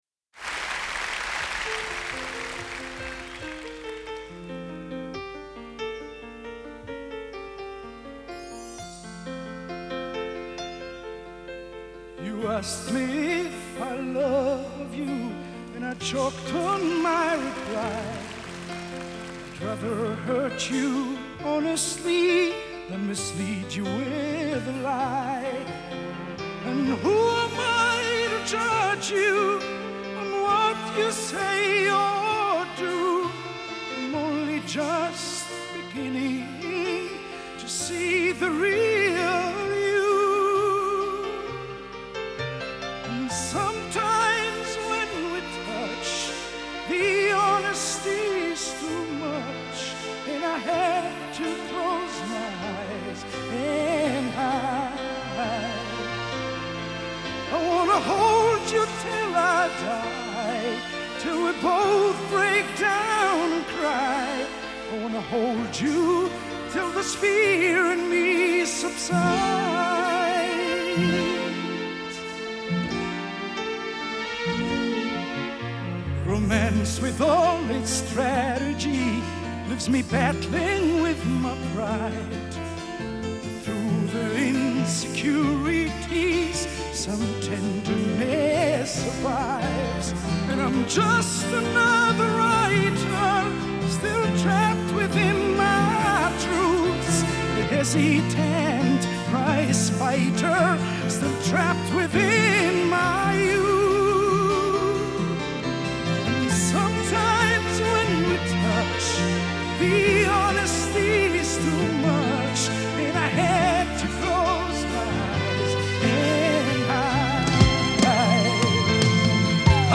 интересующая Вас песня только в концертном варианте